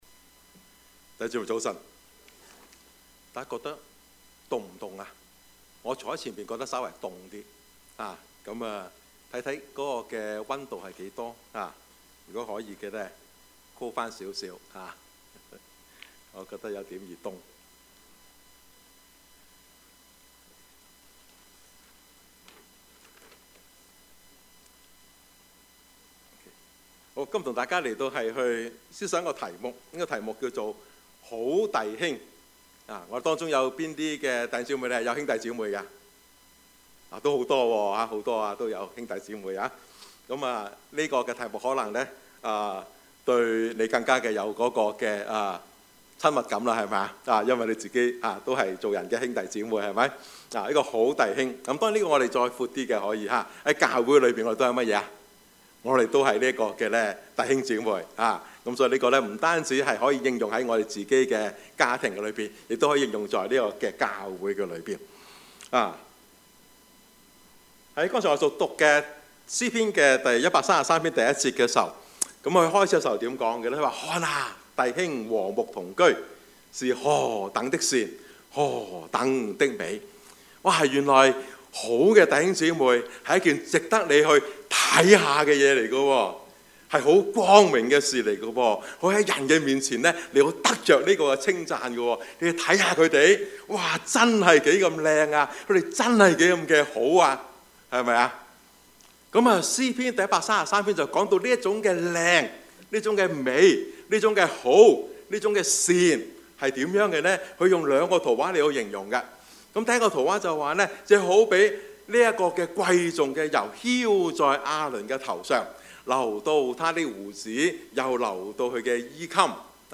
Series: 2020 主日崇拜